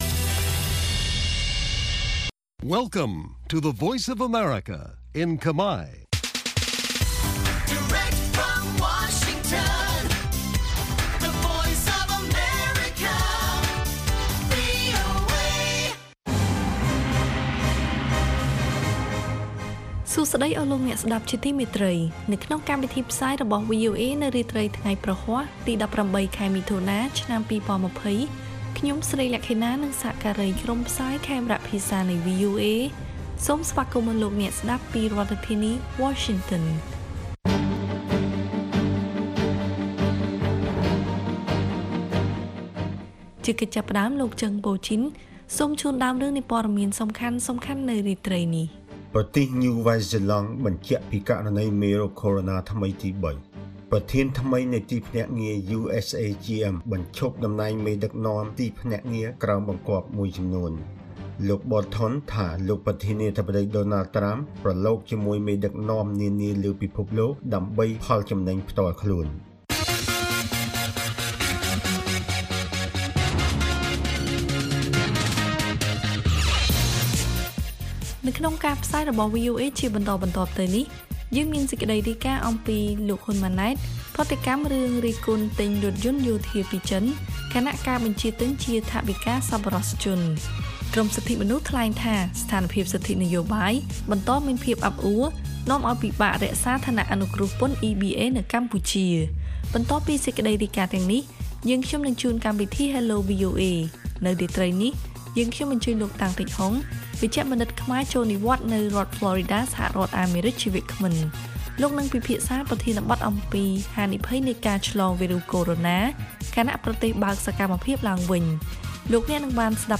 ព័ត៌មានពេលរាត្រី